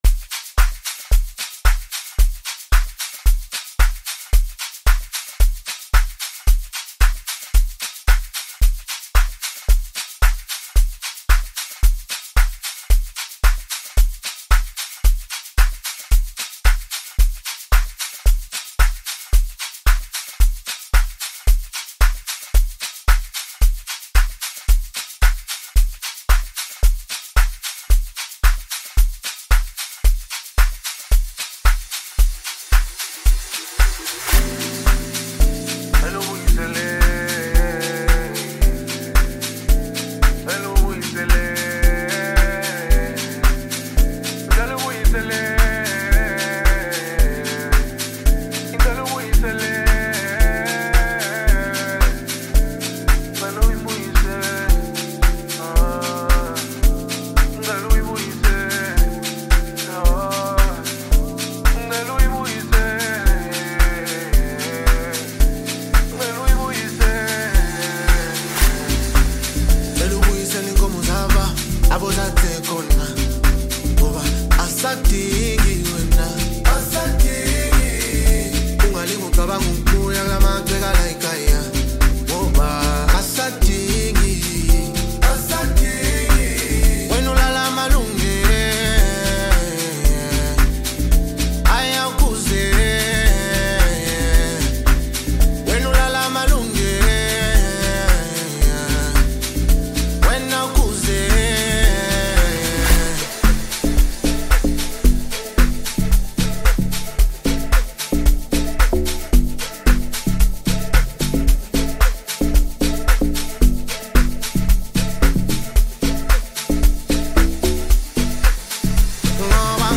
Home » South African Music